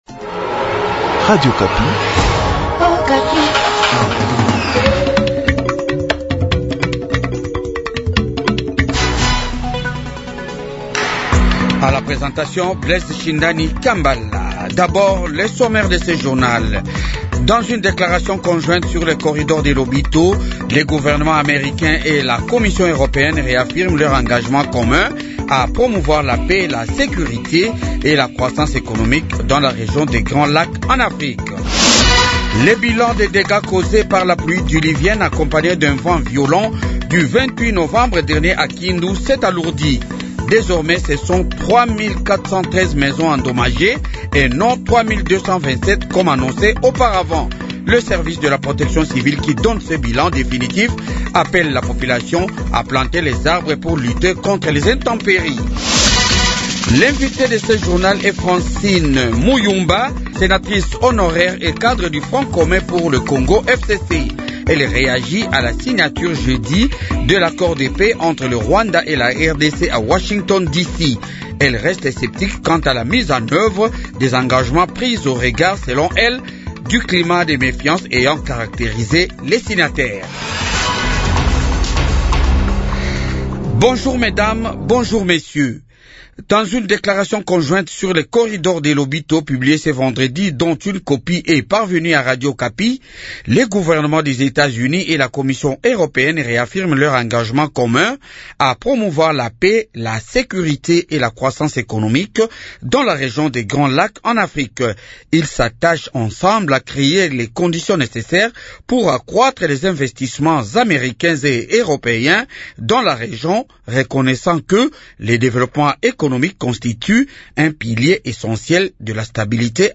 journal de 8h